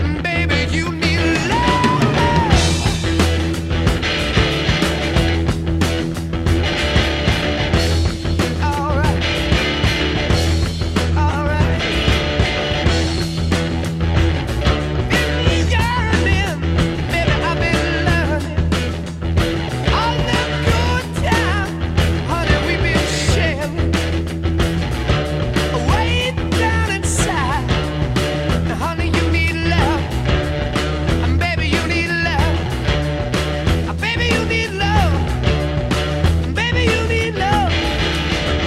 When you play it you'll notice it's in mono
About 40us delay on the left fixes it up.